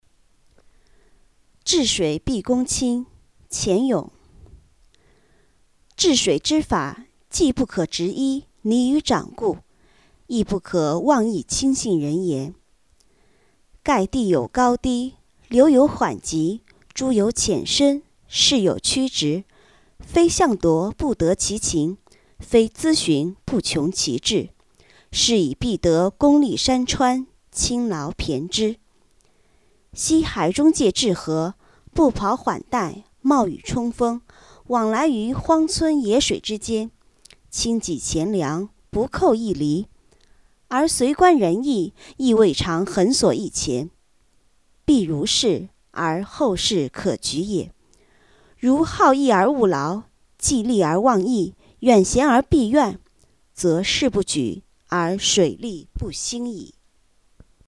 《治水必躬亲》原文及译文（含朗读）